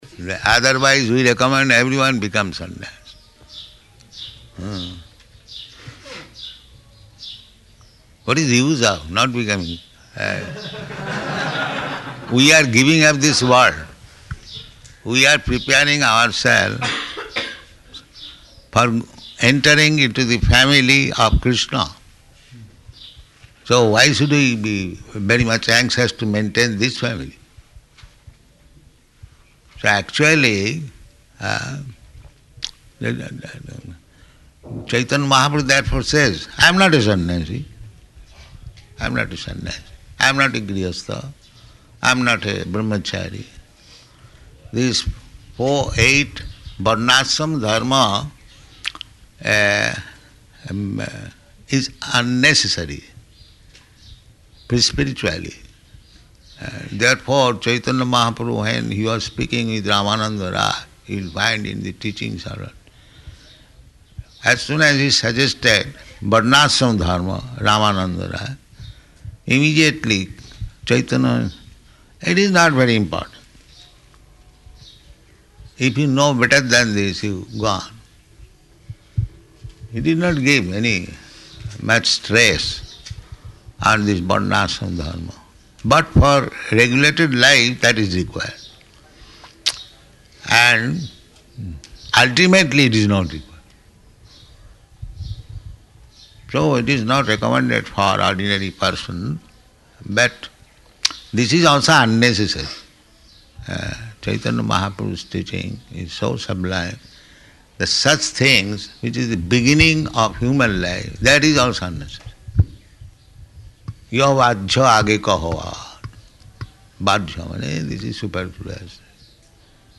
Meeting with GBC
Location: Māyāpur